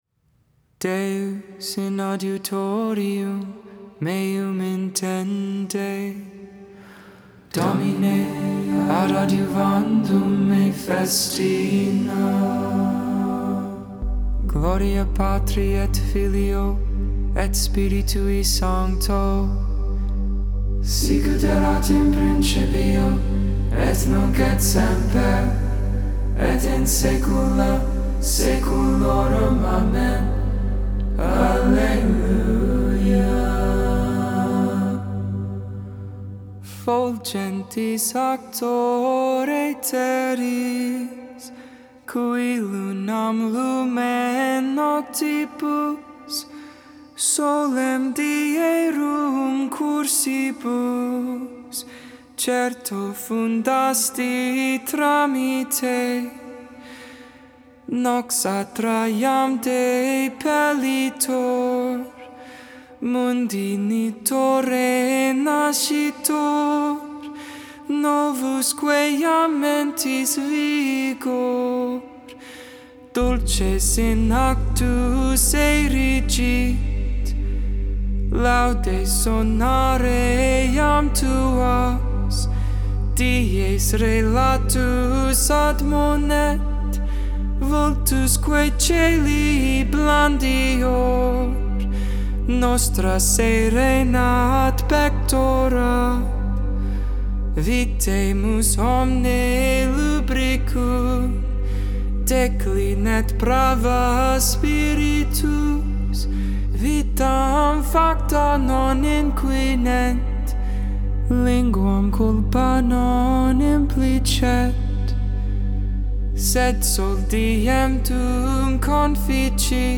Hymn
Benedictus (English, Tone 8, Luke 1v68-79) Intercessions: "Remain with us, Lord."